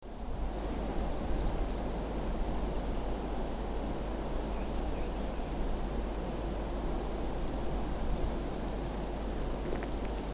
Песня малой пестрогрудки
Поет в кустарнике на просеке ЛЭП. Река Бабха, 10 июня 2012 г. В фоне - синий соловей и еще какая-то мелочь.
Sdr_0012_Bradypterus_thoracicus_song.mp3